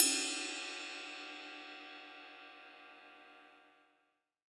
• Big Room Ride Cymbal E Key 01.wav
Royality free ride cymbal tuned to the E note. Loudest frequency: 9633Hz
big-room-ride-cymbal-e-key-01-DWw.wav